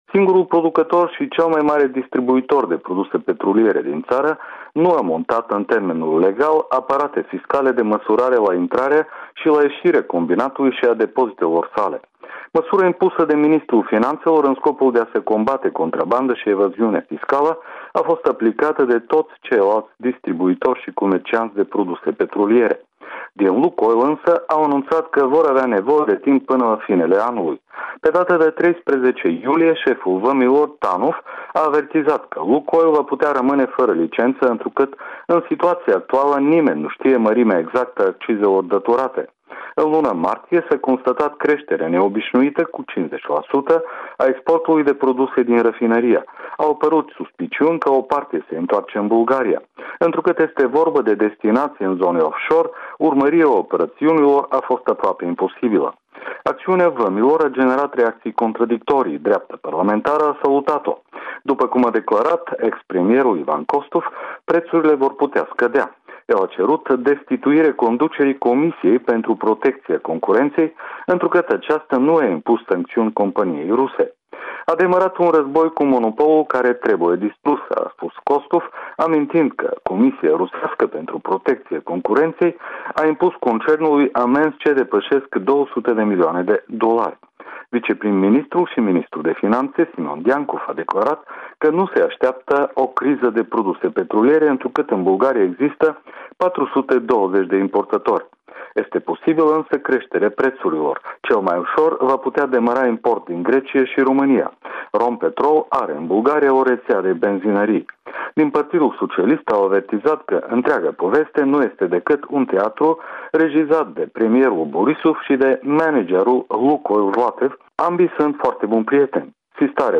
Corespondenţa zilei de la Sofia